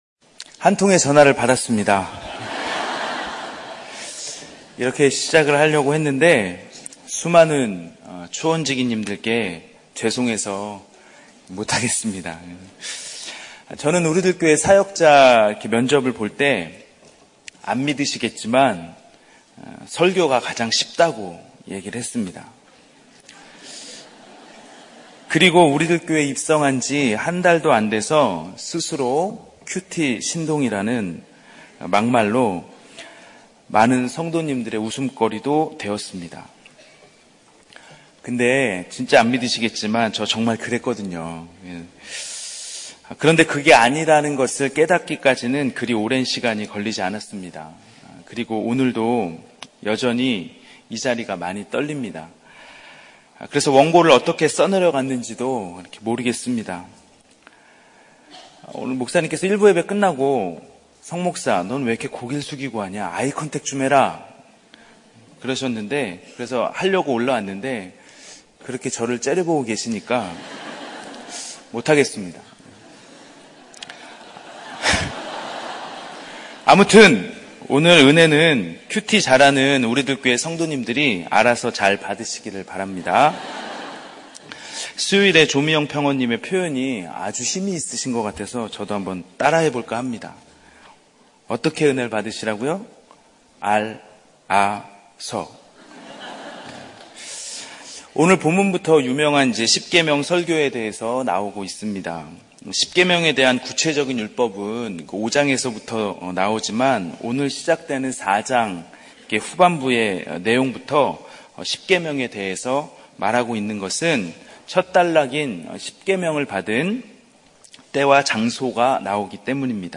설교방송